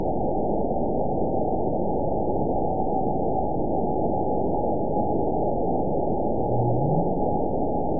event 912340 date 03/24/22 time 21:25:43 GMT (3 years, 1 month ago) score 9.57 location TSS-AB01 detected by nrw target species NRW annotations +NRW Spectrogram: Frequency (kHz) vs. Time (s) audio not available .wav